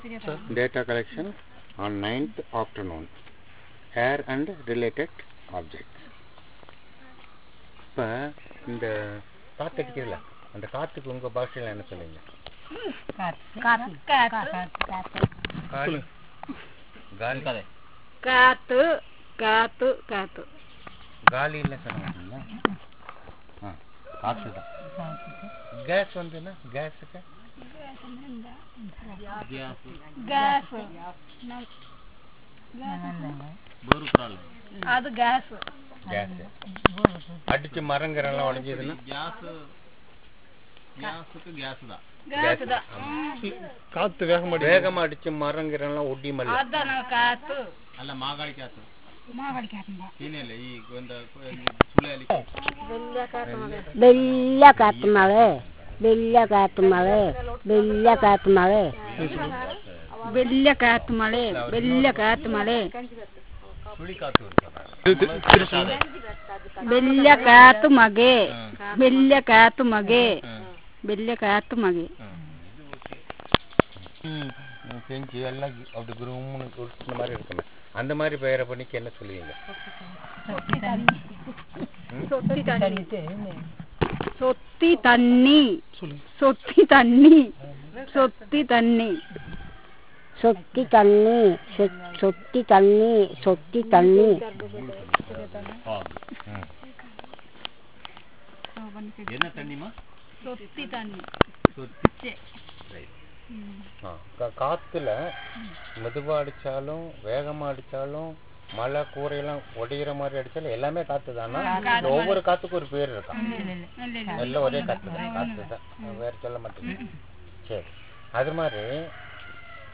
Elicitation of words related to air, fire, domestic animals, wild animals, rodents and snakes, insects, fish, birds, sounds of animals, trees, vegetables and fruits, herbs, and flowers